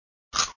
4_eat.mp3